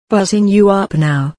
buzzing_up_boosted.protected.mp3